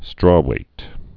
(strôwāt)